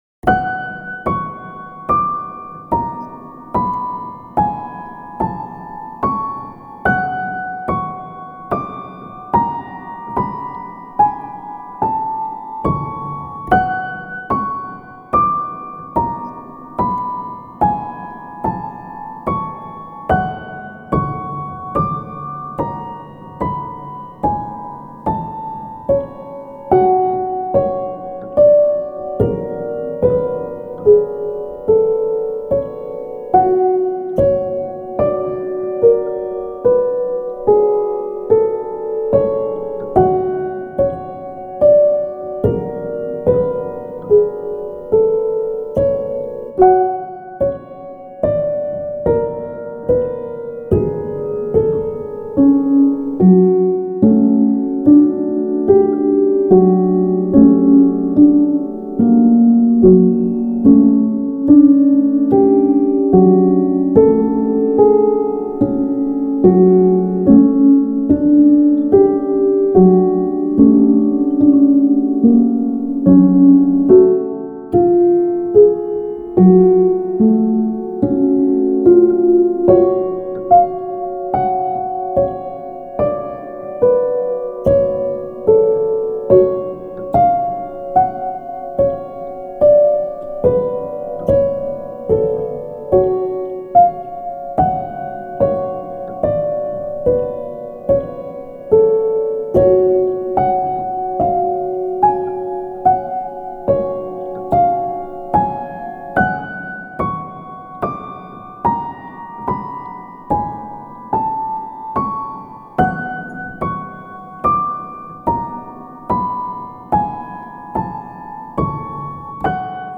冷たくじっとりとした雰囲気のピアノ曲です。